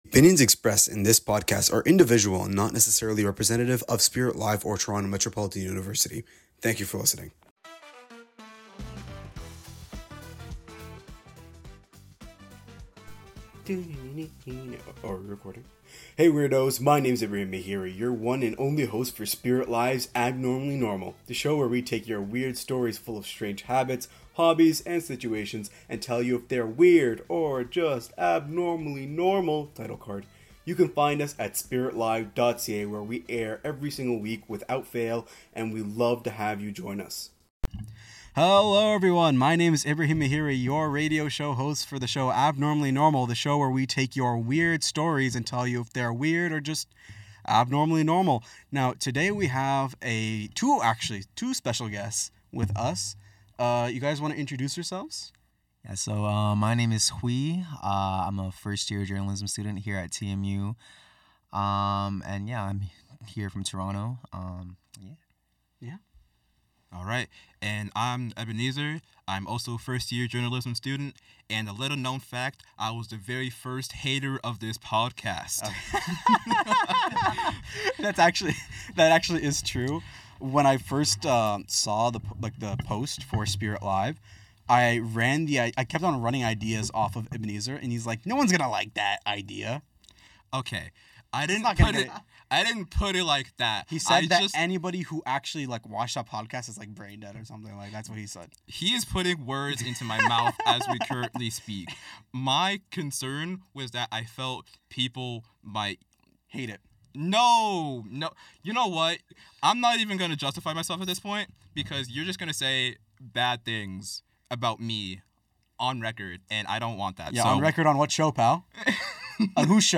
Each episode blends candid conversation with lighthearted debate, turning habits, hobbies, and odd routines into stories about what “normal” truly means.